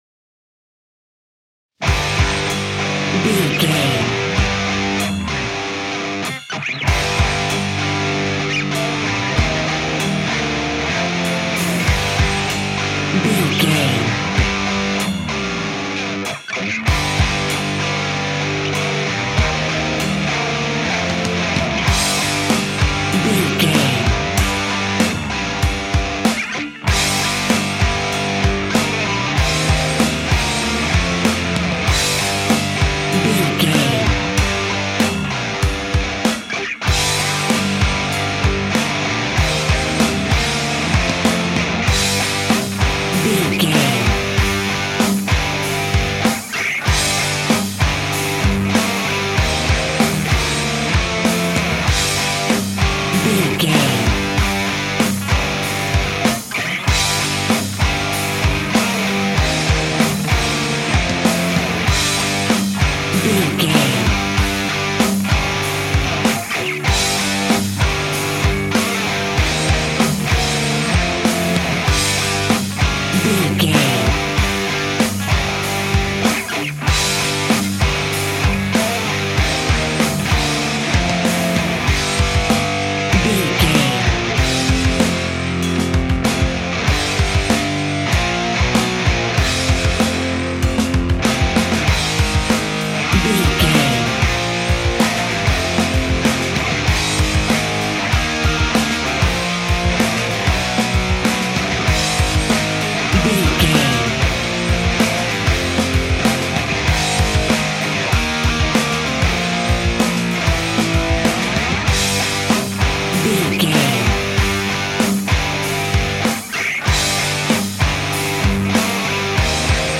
Epic / Action
Aeolian/Minor
Slow
hard rock
heavy metal
scary rock
instrumentals
Heavy Metal Guitars
Metal Drums
Heavy Bass Guitars